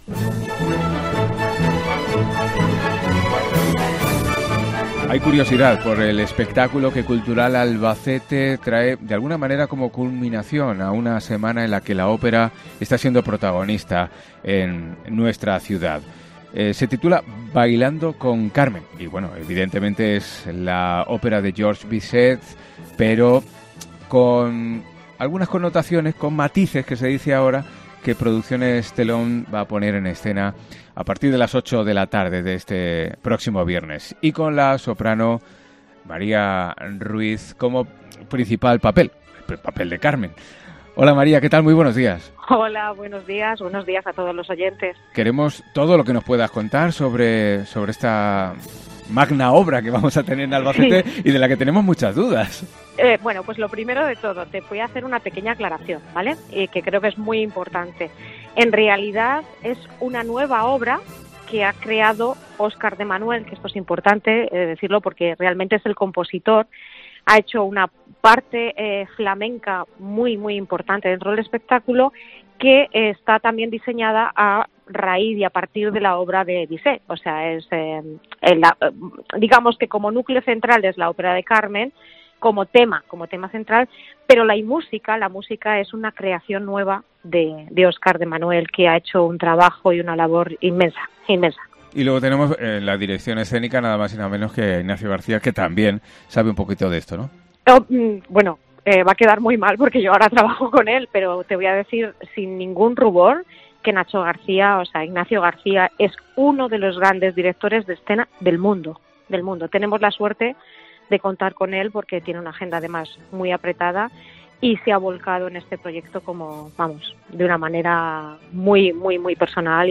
ENTREVISTA COPE